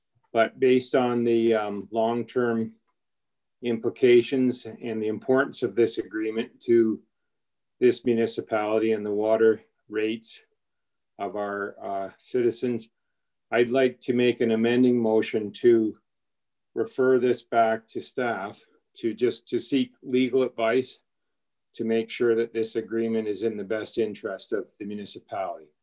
At Tuesday night’s county council meeting council reviewed a counter offer from the City of Belleville.
Councillor Ernie Margetson kicked off council discussion with an amending motion.